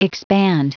Prononciation du mot expand en anglais (fichier audio)